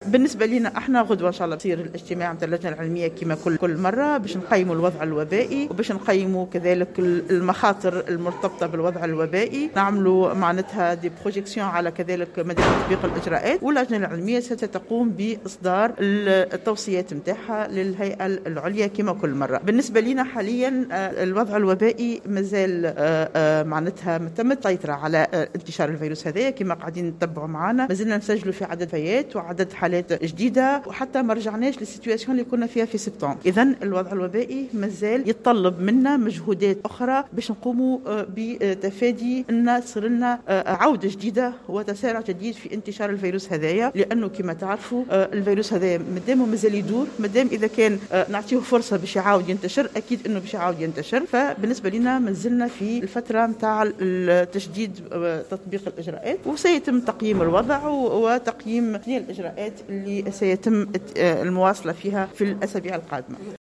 قالت الدكتورة نصاف بن علية المديرة العامة للأمراض الجديدة والمستجدة في تصريح للجوهرة "اف ام" اليوم الاثنين إن لا علم لها بوصول جرعات تلاقيح إلى تونس وذلك تعليقا على تواتر المعلومات حول وصول جرعات هبة من دولة عربية.